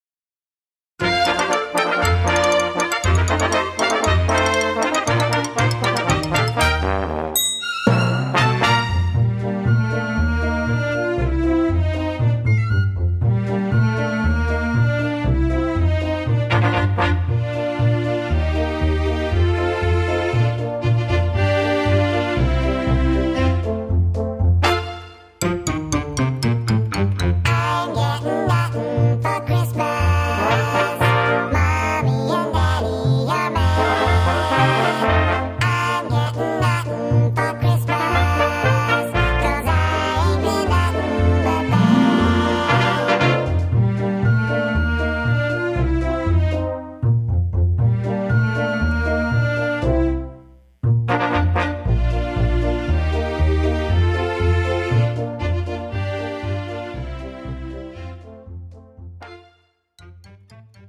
' (팝송) MR 반주입니다.